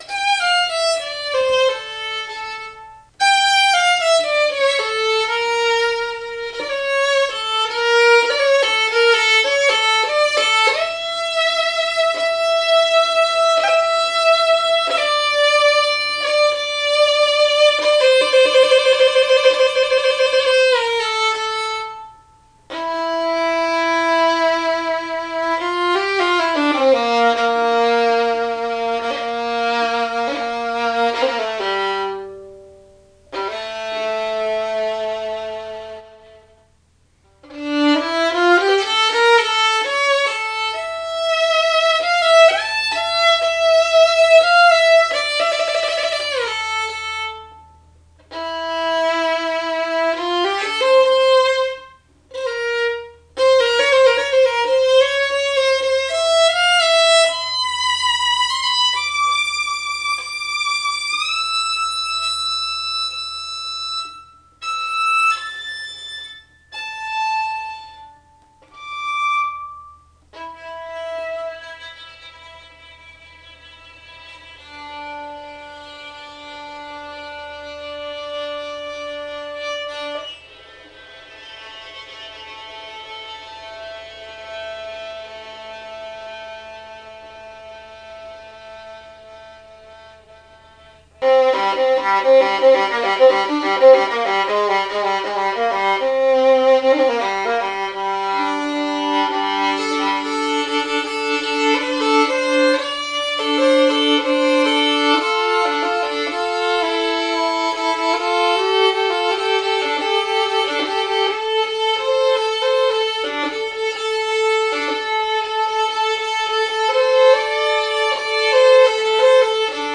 The acoustics here in our living room are exceptional, and really help to 'hear' accurately.
Click the four violins below: to hear real samples of "Rich" Tuned violins...